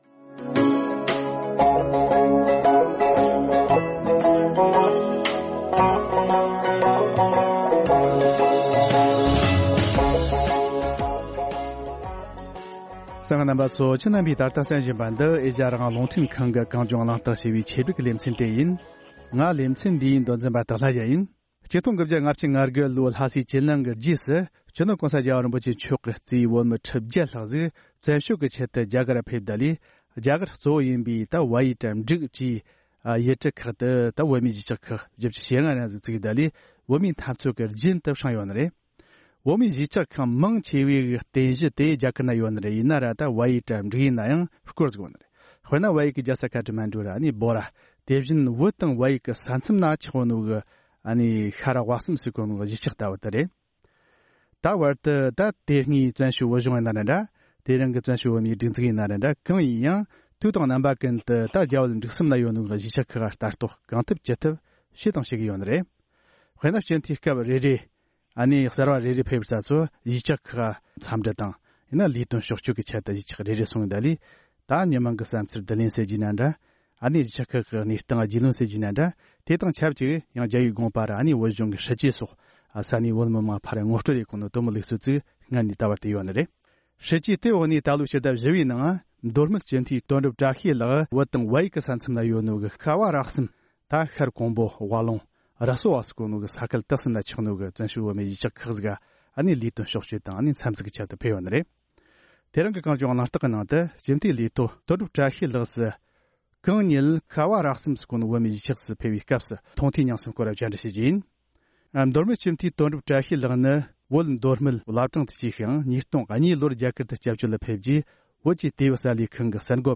༄༅།། དེ་རིང་གི༼གངས་ལྗོངས་གླེང་སྟེགས༽ཀྱི་ནང་དུ། སྤྱི་འཐུས་ལས་ཐོག་དོན་འགྲུབ་བཀྲ་ཤིས་ལགས་ད་ལོའི་སྤྱི་ཟླ་བཞི་བའི་ནང་བོད་དང་བལ་ཡུལ་གྱི་མཐའ་མཚམས་སུ་ཆགས་པའི་ཤ་ཁུམ་པོ་དང་ཝ་ལུང་། ར་སུ་ཝ་བཅས་ས་ཁུལ་གསུམ་གྱི་ཁྱབ་ཁུངས་སུ་ཡོད་པའི་བོད་མིའི་གཞིས་ཆགས་ཁག་ཏུ་གཞུང་འབྲེལ་ཕྱོགས་སྐྱོད་ལ་ཕེབས་པའི་སྐབས་ཀྱི་མཐོང་ཐོས་མྱངས་གསུམ་སྐོར་གླེང་མོལ་བྱེད་རྒྱུ་ཡིན།